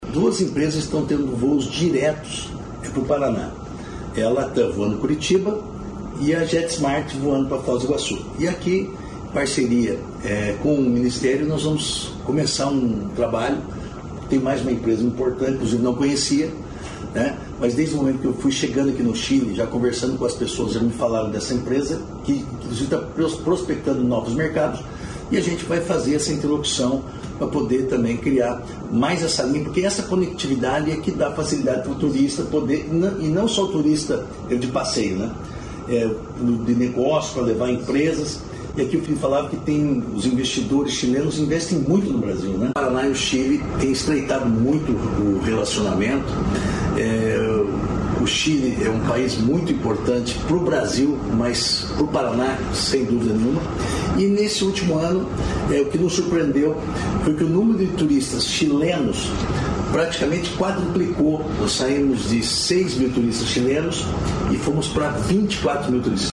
Sonora do secretário do Turismo, Márcio Nunes, sobre a parceria entre o Paraná e o Chile